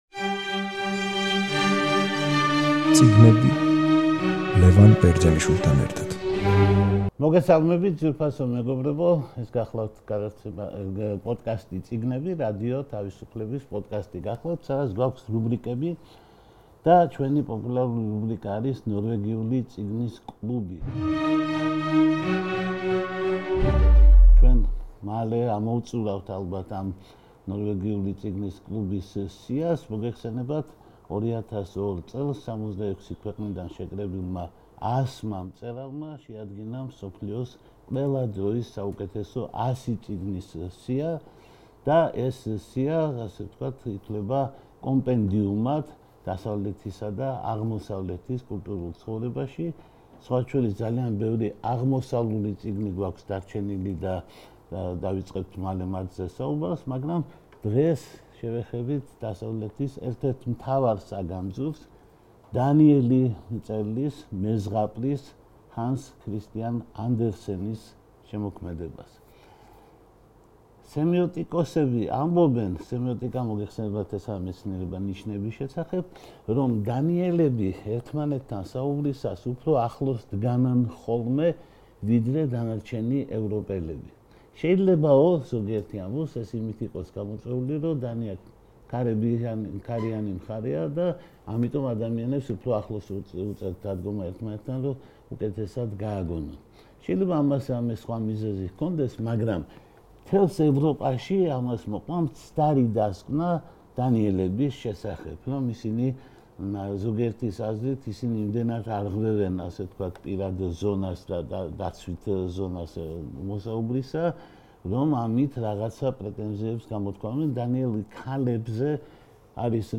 რადიო თავისუფლების პოდკასტი „წიგნები“ და რუბრიკა „ნორვეგიული წიგნის კლუბი“ გთავაზობთ საუბარს დიდი მეზღაპრის, ჰანს ქრისტიან ანდერსენის შემოქმედებაზე. ანდერსენი ჭეშმარიტი კონტრასტების კაცი იყო: მეწაღისა და მრეცხავის ოჯახში დაიბადა და დანიის მეფის მეგობარი იყო; უფროსებისთვის წერდა და ყოველთვის...